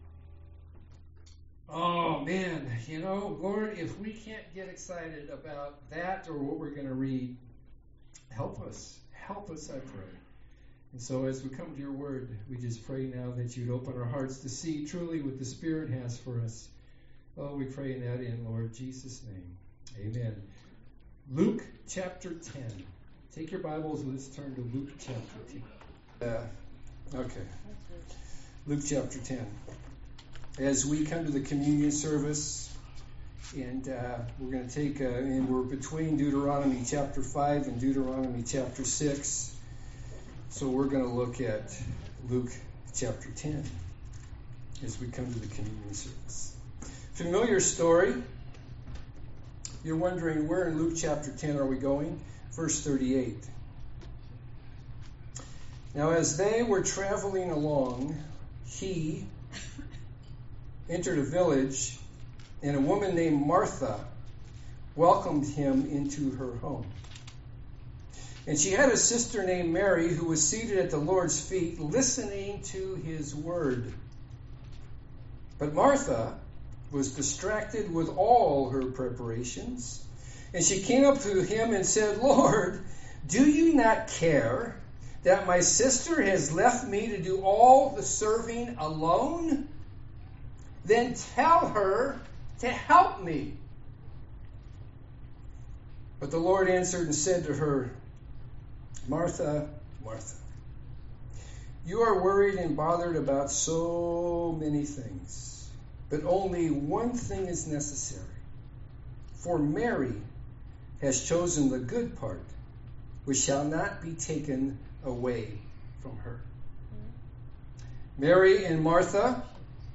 Serm4Sep2022.mp3